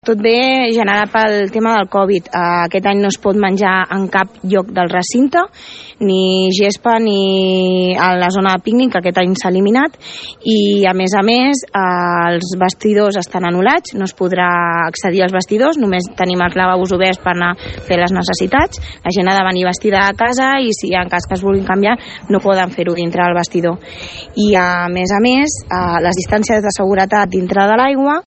Sònia González, regidora d’Esports de l’Ajuntament de Palafolls, ha explicat aquest matí des de la mateixa piscina que des del consistori s’havia establert l’obertura de la piscina municipal com a prioritat aquest estiu, malgrat la Covid-19, sempre que es poguessin complir les mesures de seguretat. En aquest sentit, la regidora d’Esports explica que arran del Covid-19 s’han hagut d’implementar diverses mesures per prevenir contagis, com ha estat la prohibició de menjar al recinte, el tancament dels vestidors i l’obertura de només 2 lavabos.